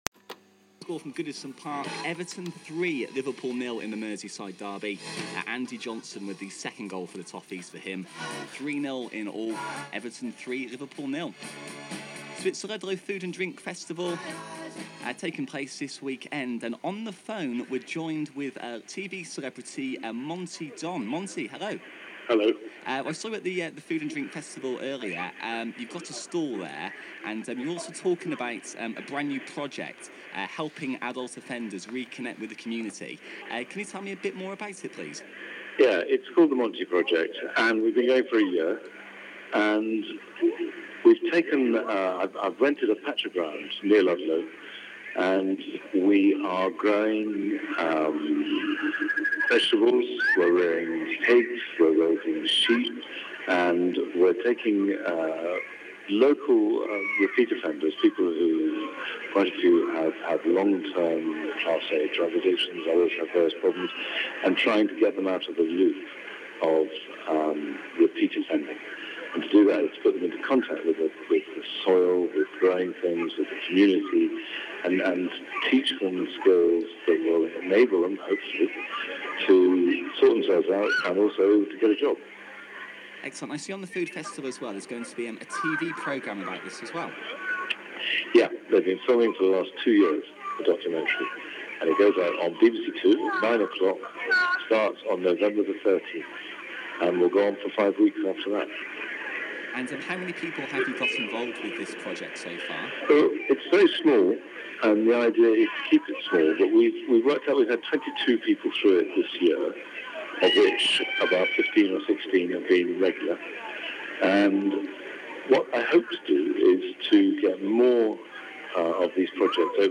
Archive Interview from 9th September 2006 on Sunshine 855